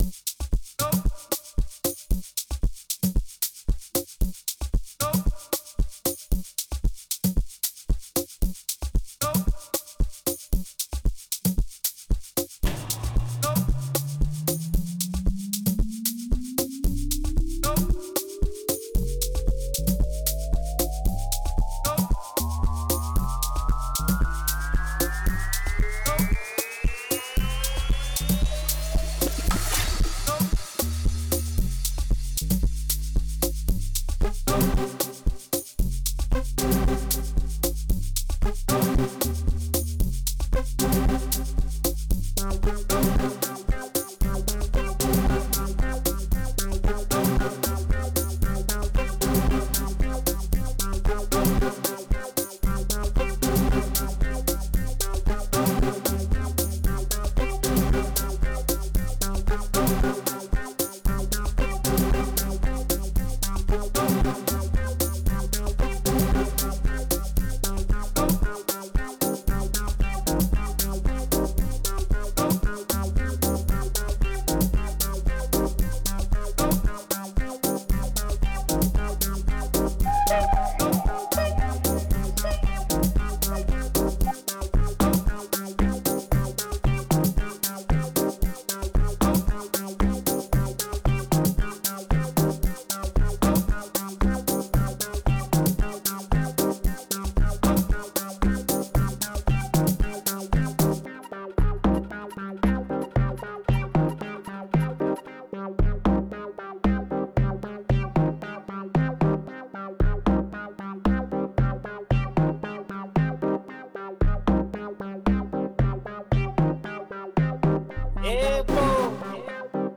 08:58 Genre : Amapiano Size